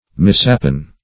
Mishappen \Mis*hap"pen\